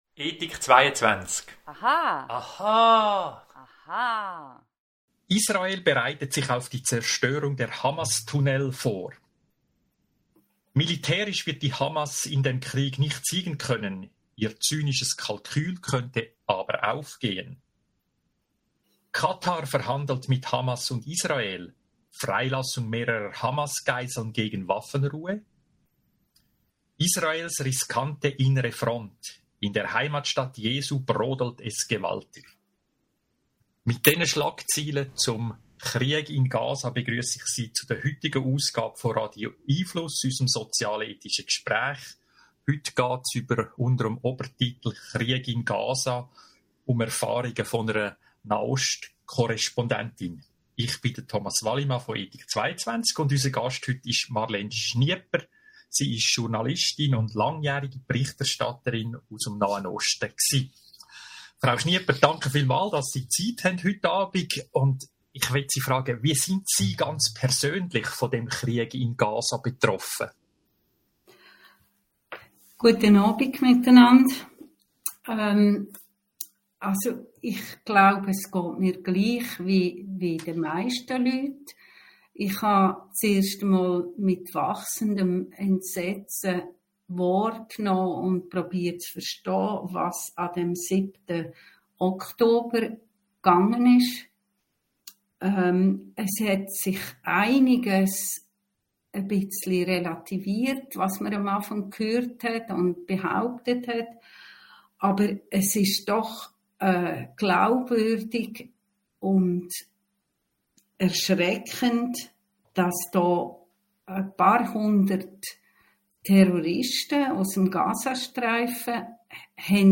zum Thema hören Sie im Podcast unseres Gesprächs vom 8. November 2023